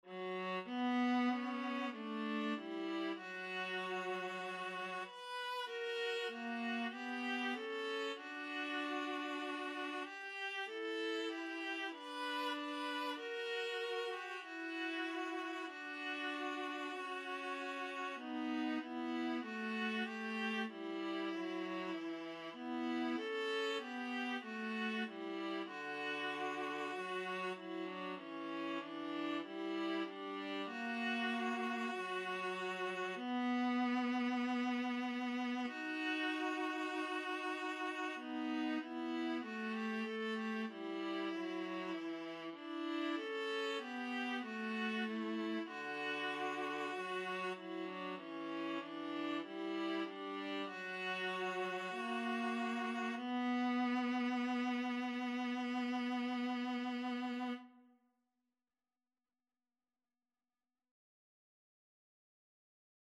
El cant dels ocells (The song of the birds) (Trad. Catalan Christmas song) Free Sheet music for Viola Duet
el_cant_del_ocells_2VLA.mp3